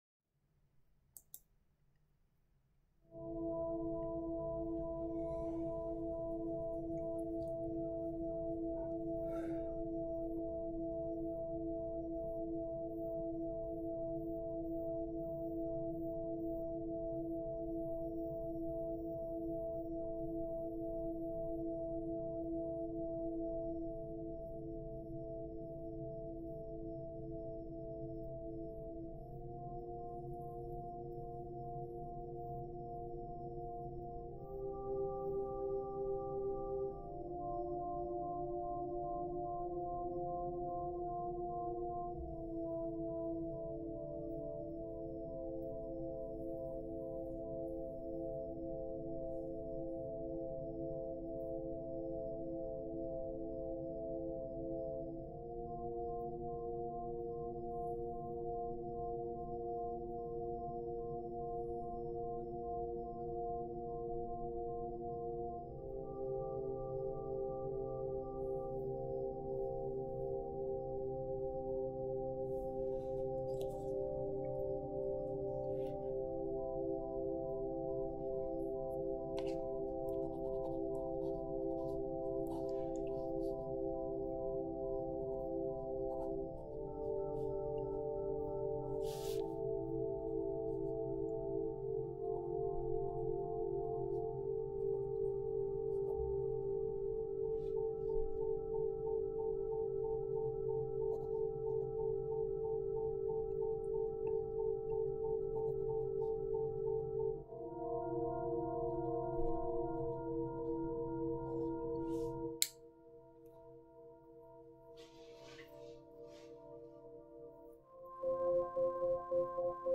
meter = "Largo"